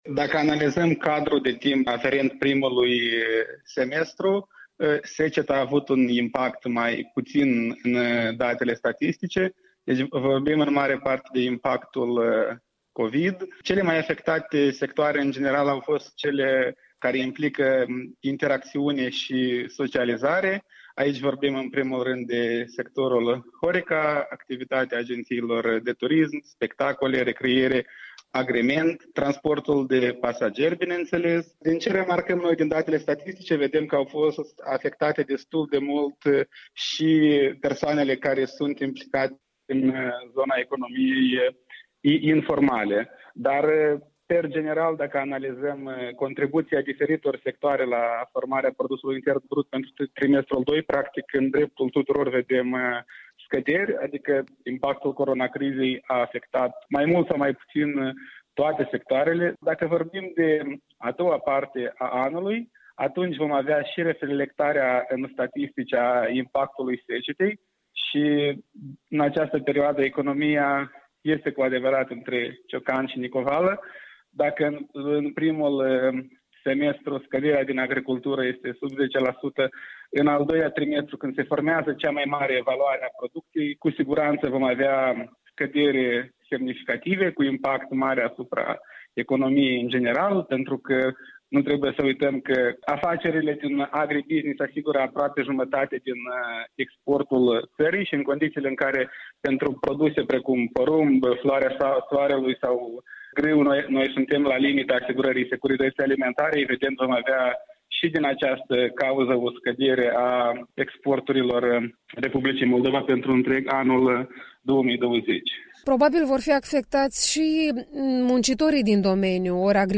Interviul dimineții la EL